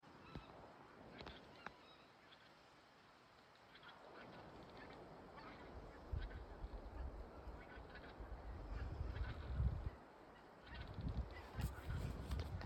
Zoss Anser sp., Anser sp.
Administratīvā teritorijaValkas novads
Skaits120 - 150